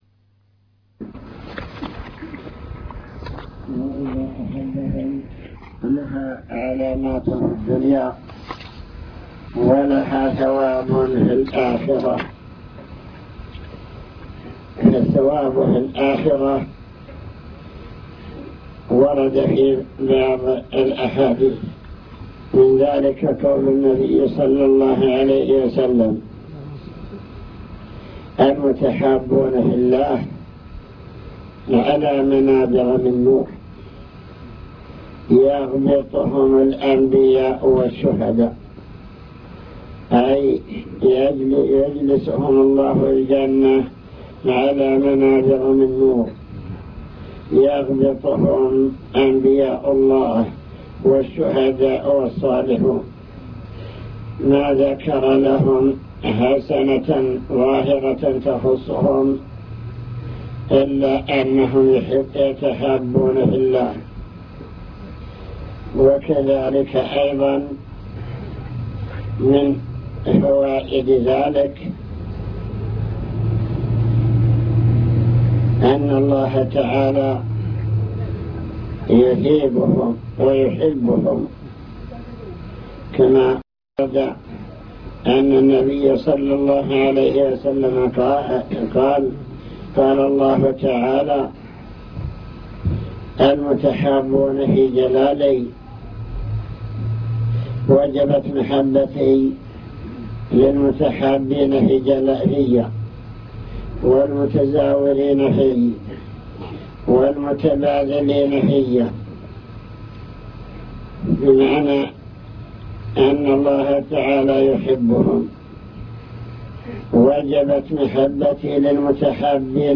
المكتبة الصوتية  تسجيلات - لقاءات  كلمة لمدرسي تحفيظ القرآن المحبة في الله فضلها ومظاهرها